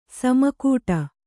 ♪ sama kūṭa